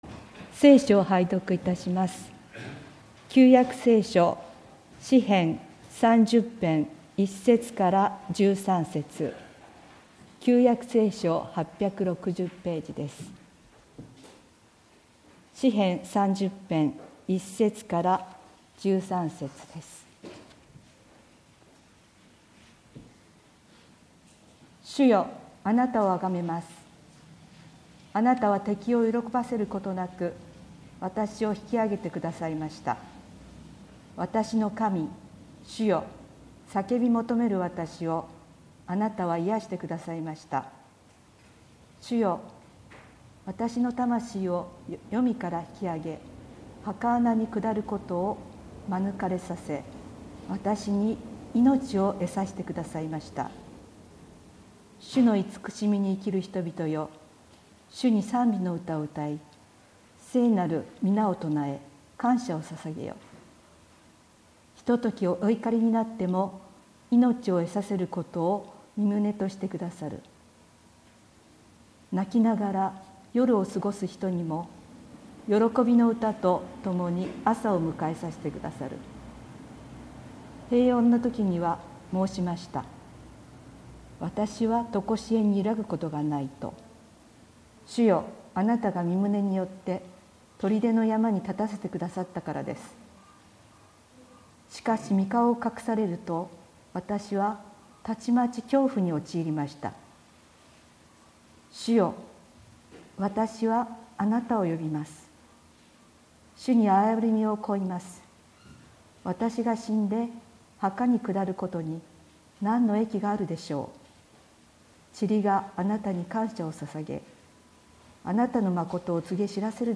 2020年9月27日（日）主日礼拝説教